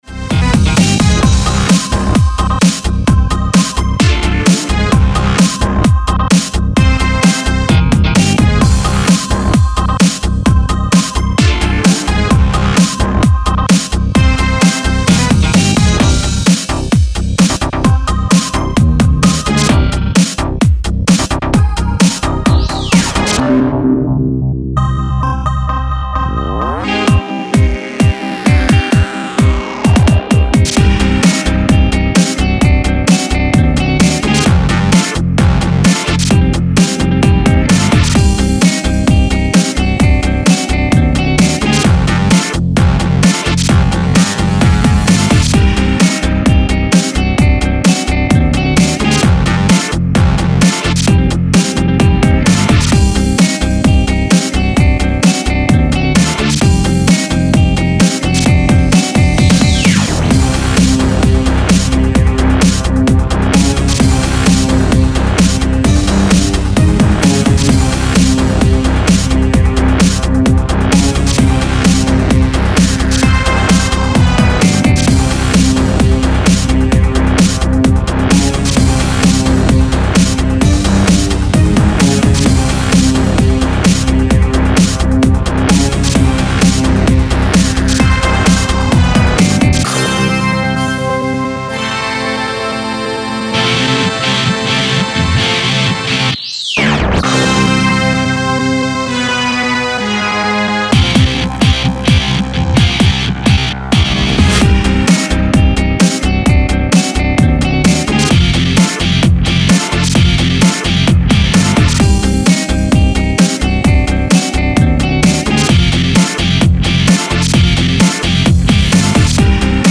Genres Breaks